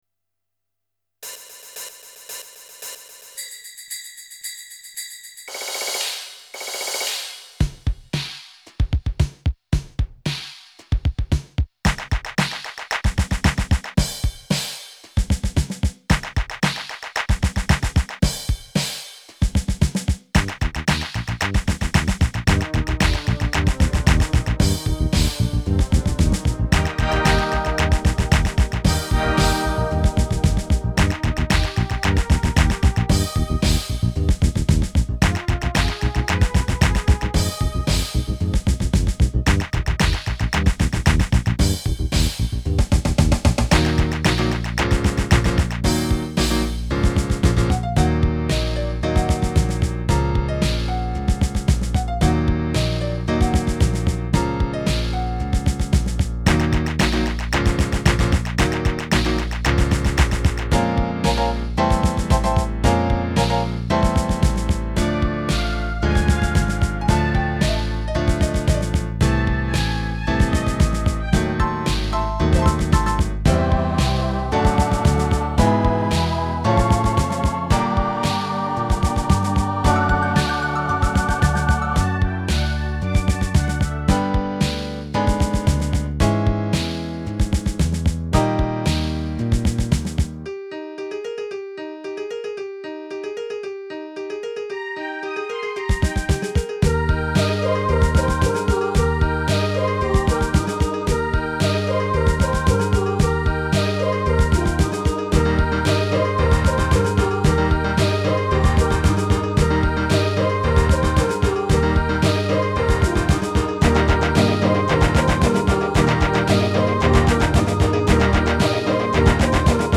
Solid infection rhythms with an international flavour